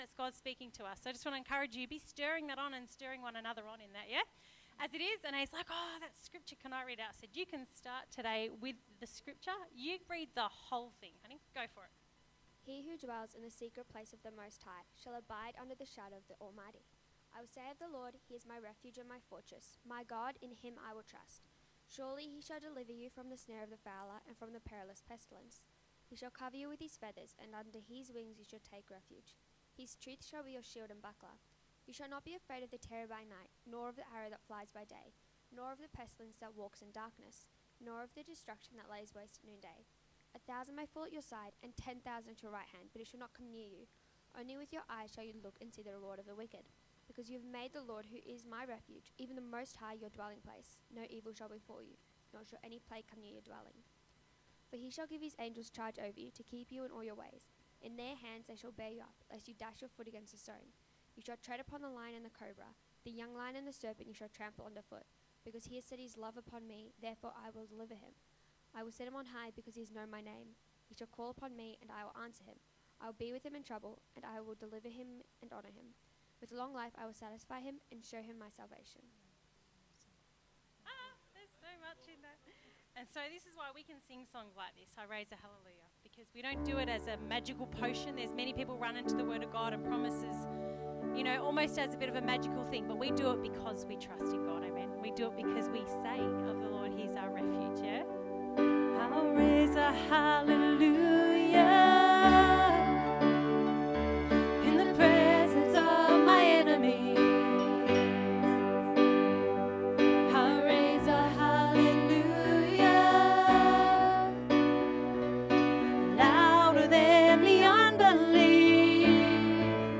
Church Service – 22nd March